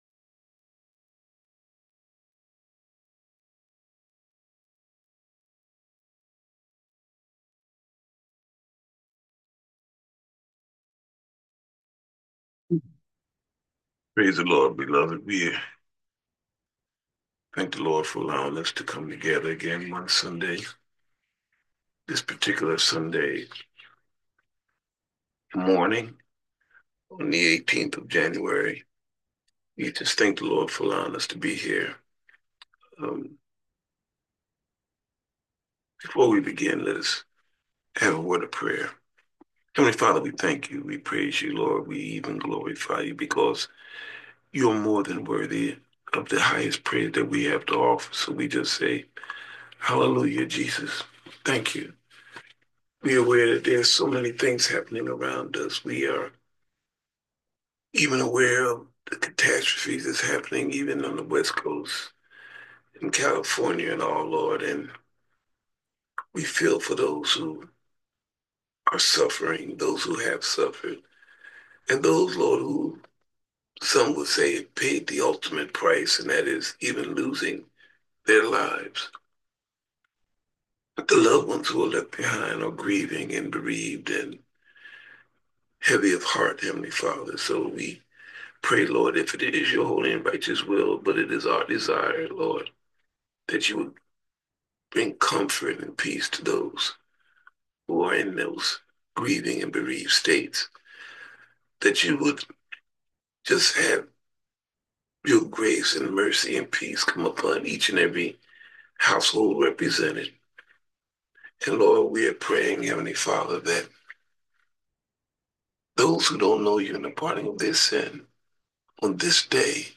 Sermon delivered by